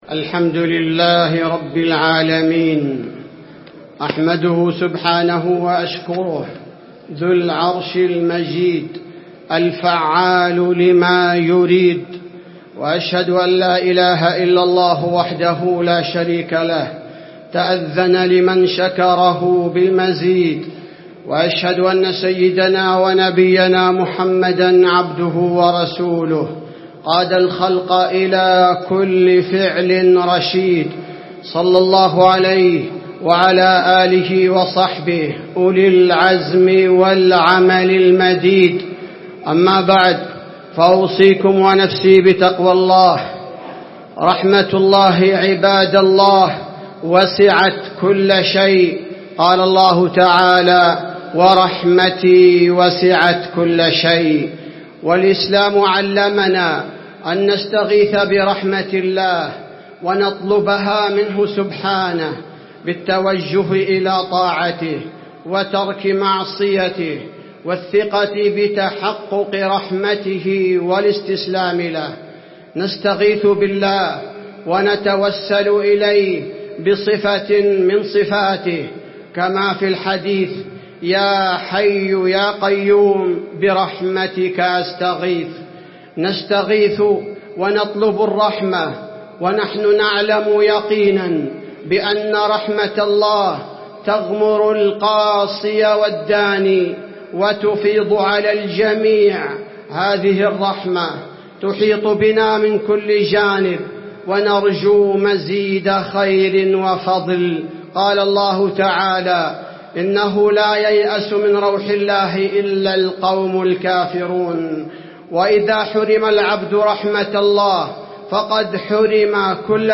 خطبة الاستسقاء - المدينة- الشيخ عبدالباري الثبيتي
تاريخ النشر ٣٠ ربيع الأول ١٤٣٩ هـ المكان: المسجد النبوي الشيخ: فضيلة الشيخ عبدالباري الثبيتي فضيلة الشيخ عبدالباري الثبيتي خطبة الاستسقاء - المدينة- الشيخ عبدالباري الثبيتي